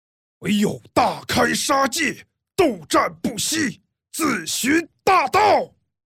[CG人声]
圣僧 金刚【斗战神 – 圣僧第二形态】：粗犷浑厚的暴力金刚，两种音色转换自然，展现角色双面特性。